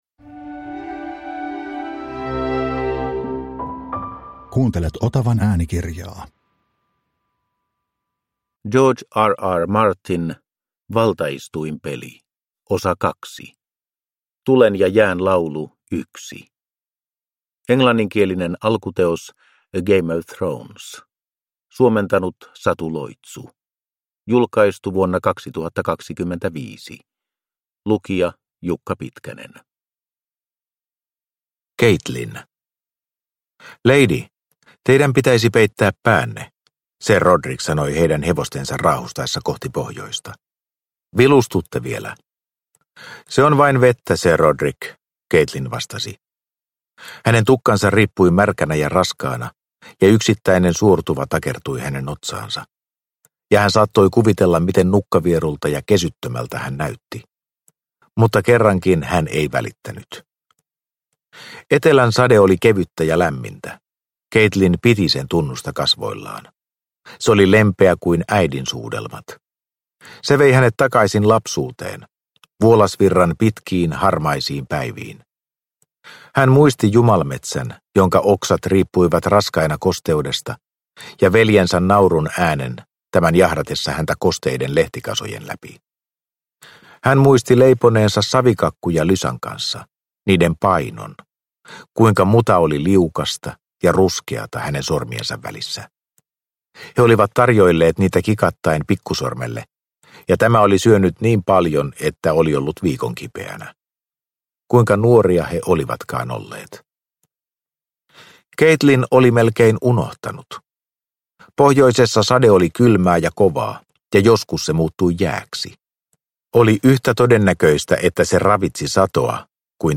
Valtaistuinpeli 2 – Ljudbok
Äänikirjan toinen osa.